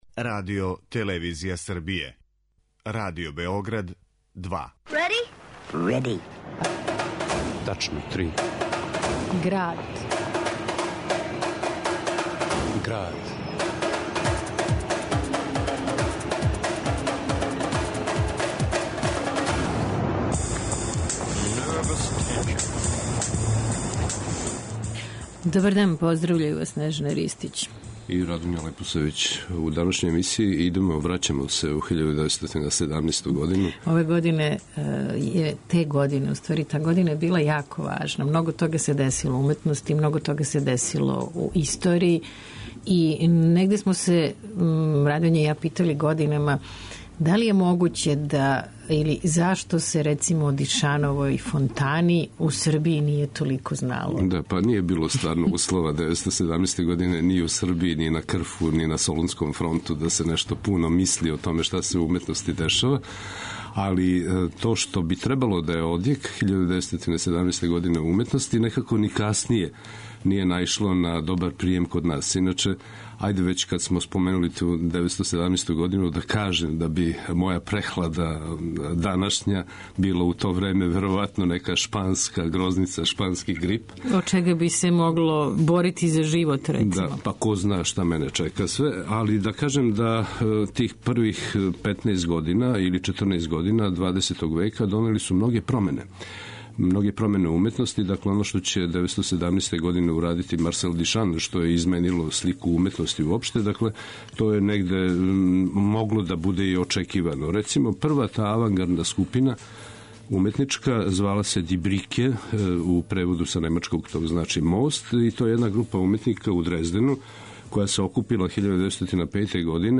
U Gradu , uz fičer O Fontani i kornjačama - što je zapravo dokumentarni radio esej o ratu, umetnosti, revolucijama, Srbiji, Evropi, svetu u 20. veku... - biće reči o jednom prošlom vremenu koje itekako određuje sadašnjost.